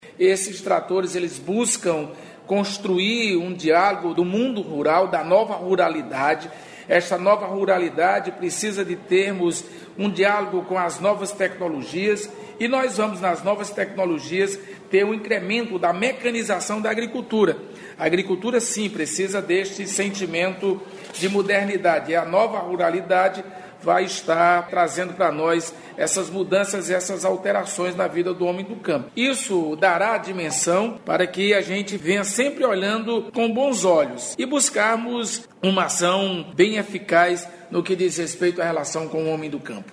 O secretário do Desenvolvimento Agrário, Francisco de Assis Diniz, destacou que os novos tratores representam mais tecnologia para a atuação do homem do campo.